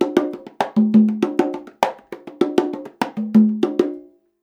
100 CONGAS19.wav